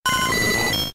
Cri de Roucoups K.O. dans Pokémon Diamant et Perle.